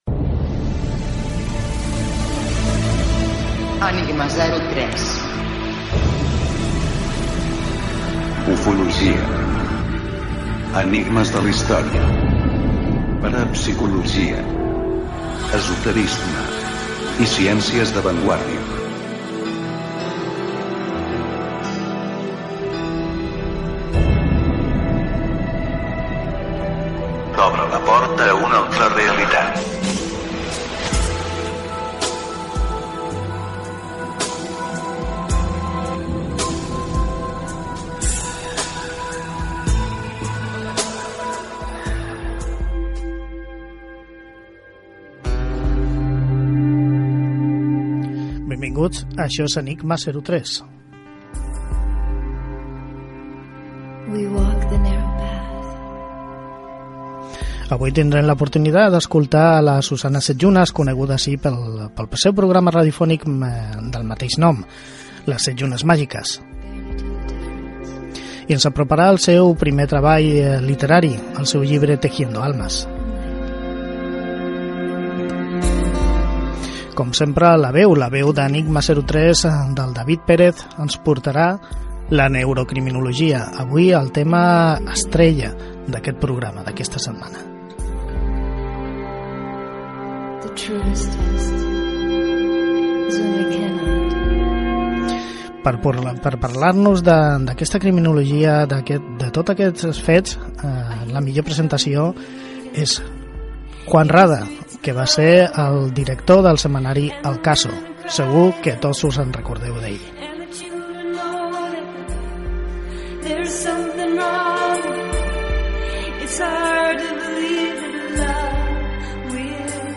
Careta del programa, sumari, entrevista